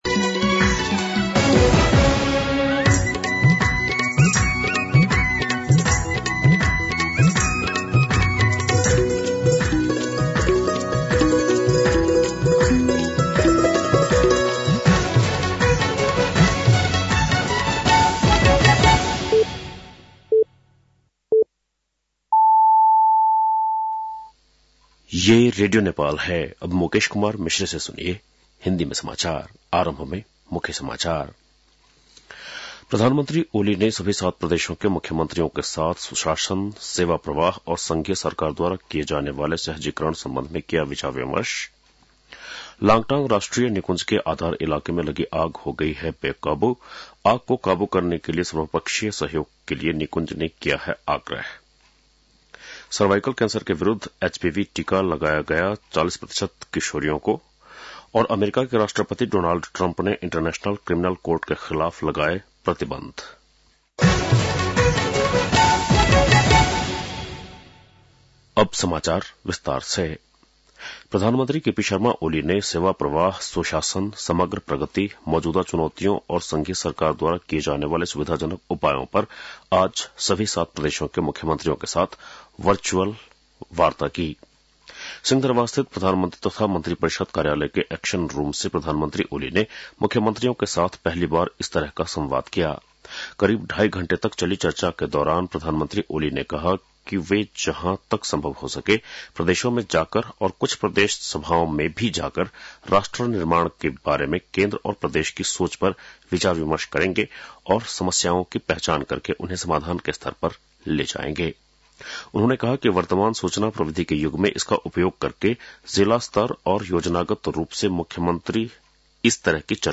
बेलुकी १० बजेको हिन्दी समाचार : २६ माघ , २०८१